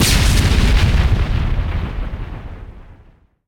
grenadeexplode.ogg